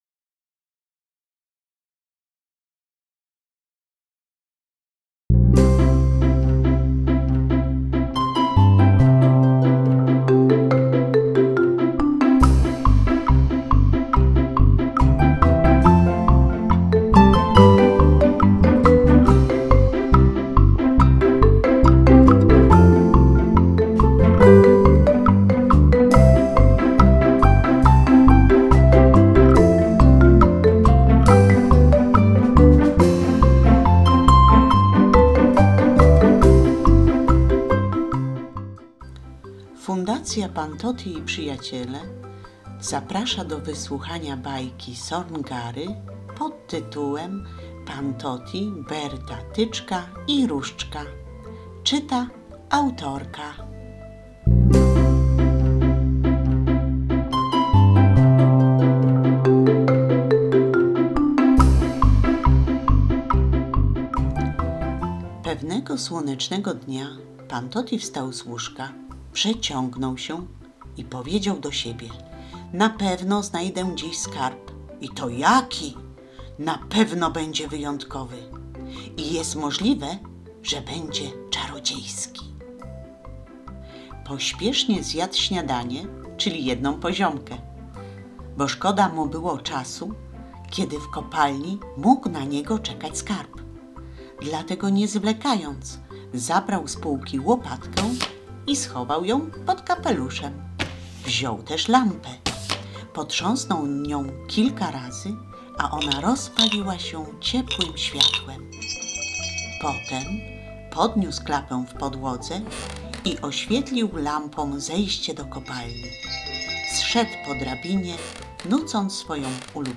Pan Toti, Berta, Tyczka i różdżka - Sorn Gara - audiobook